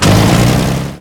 tank-engine-load-reverse-3.ogg